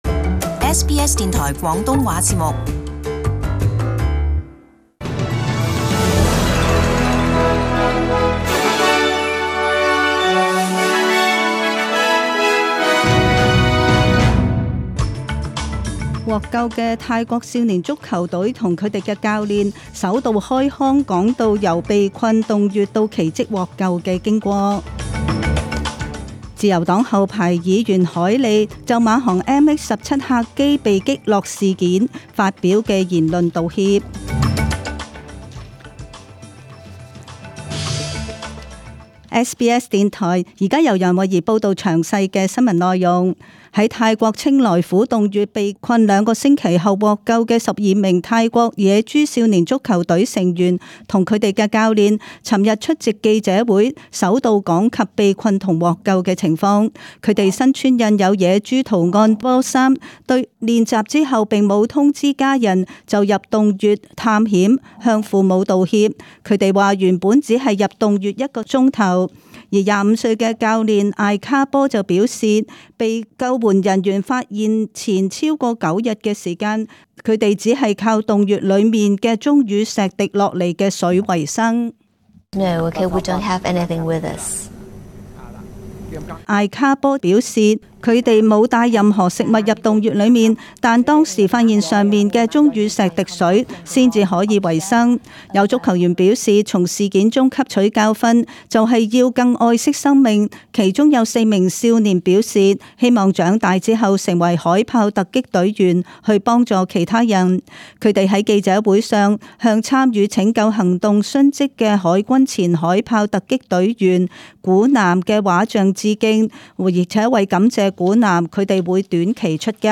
SBS中文新闻 （七月十九日）
请收听本台为大家准备的详尽早晨新闻。